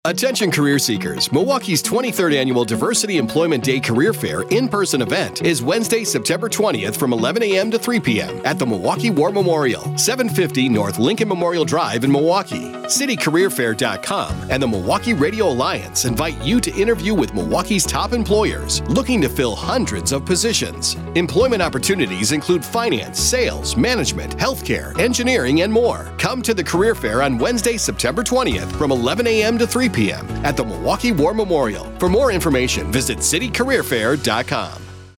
:30 Radio Ad